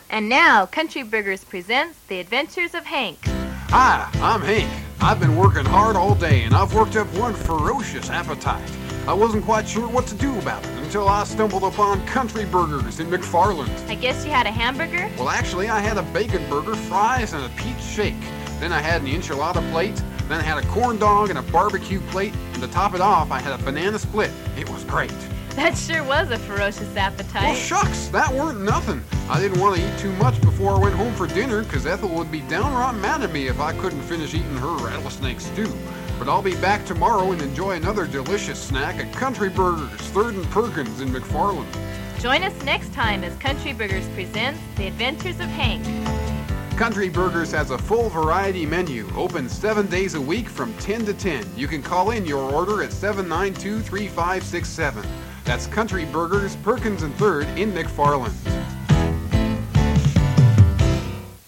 a crazy promotion for a McFarland restaurant
country_burgers60.mp3